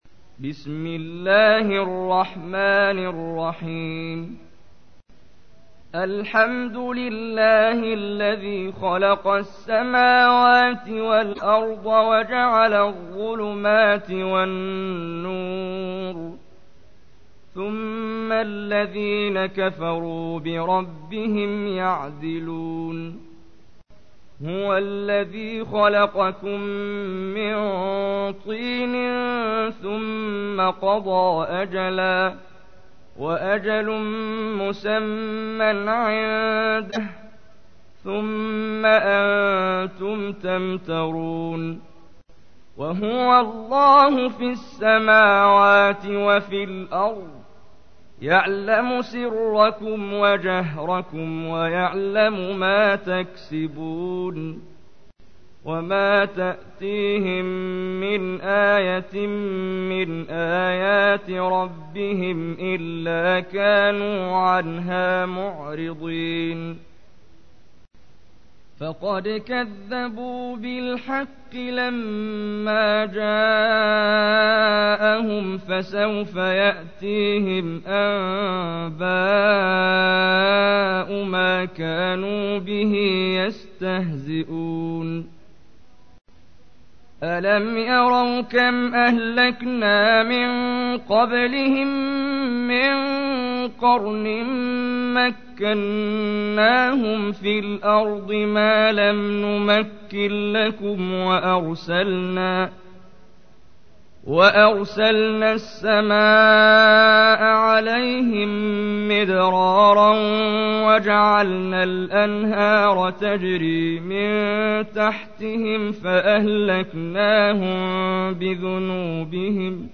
تحميل : 6. سورة الأنعام / القارئ محمد جبريل / القرآن الكريم / موقع يا حسين